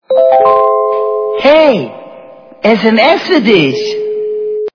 » Звуки » звуки для СМС » Информация - СМС
При прослушивании Информация - СМС качество понижено и присутствуют гудки.